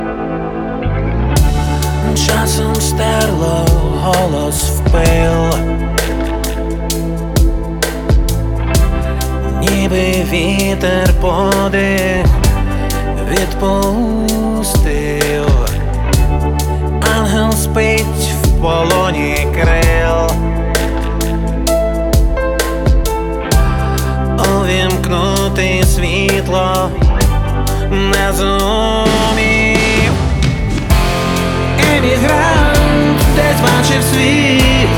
Pop Rock Pop
Жанр: Поп музыка / Рок / Украинские